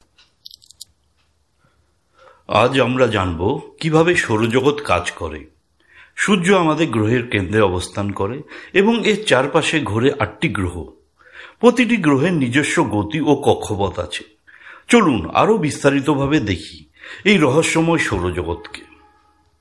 E-learning Demo – Bengali (Instructional Tone)
Voiceover_Elearning_Explainer.mp3